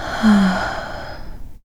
SIGH 7.wav